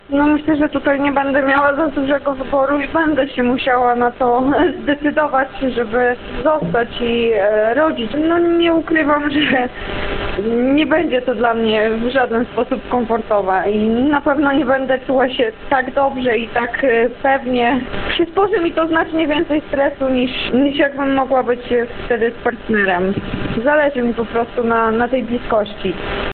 – Zdecydowanie lepiej czułabym się, gdyby partner był przy mnie w tej wyjątkowej chwili – dodaje słuchaczka.